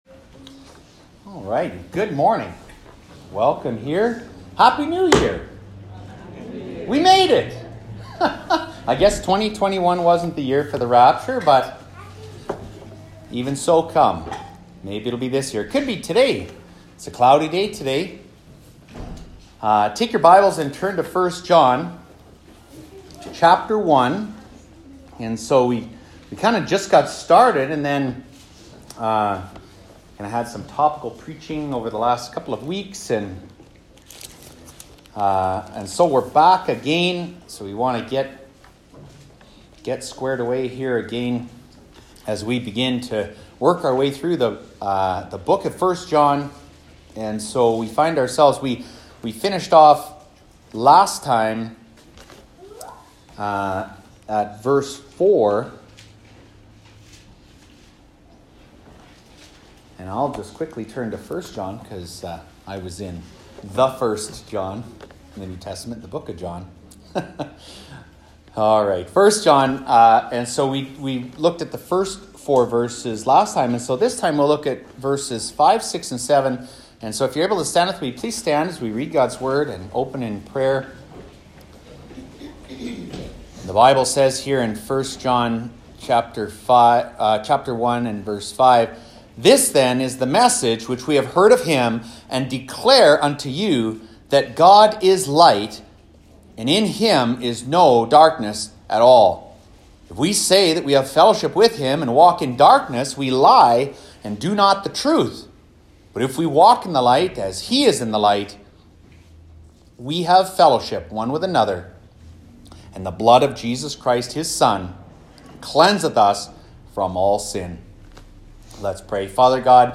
Sermons | Harvest Baptist Church